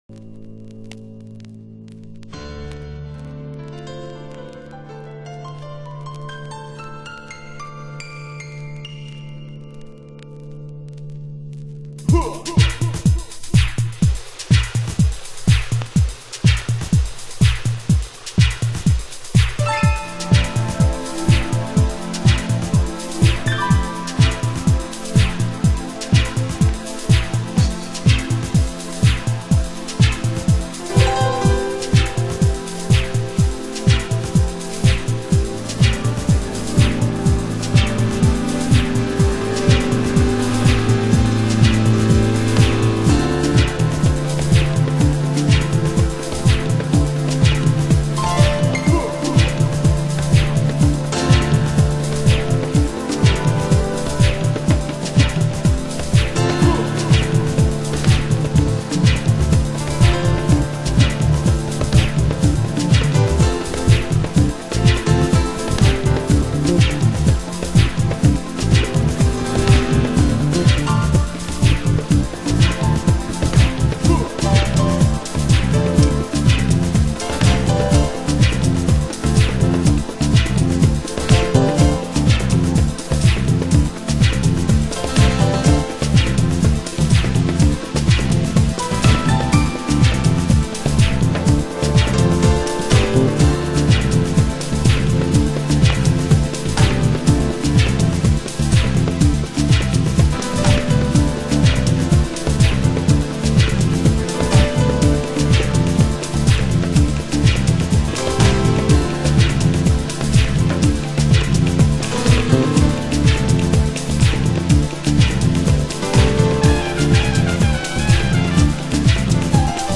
盤質：チリパチノイズ有/A面中盤に傷ノイズ 有/ラベルに記入有　　ジャケ：大きく破れ